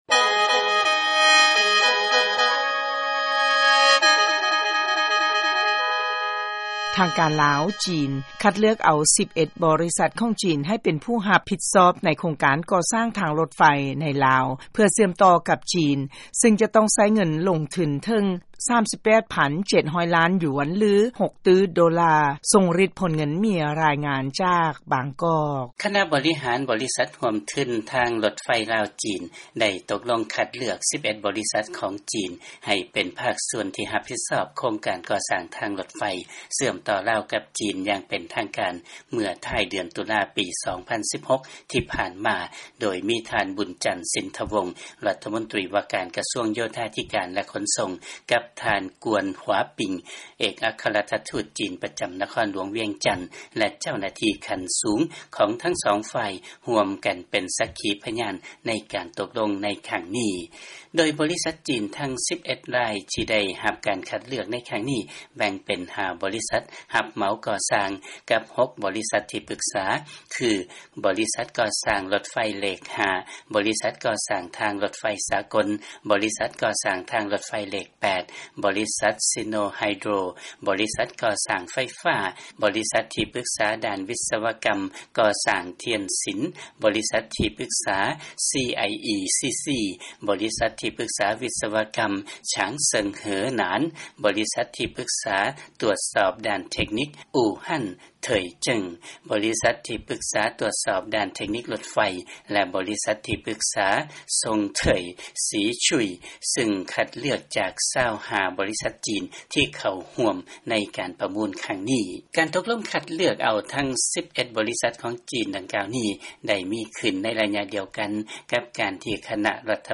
ເຊີນຟັງລາຍງານ ທາງການລາວ-ຈີນ ຄັດເລືອກເອົາ 11 ບໍລິສັດ ໃຫ້ຮັບຜິດຊອບ ການກໍ່ສ້າງທາງລົດໄຟ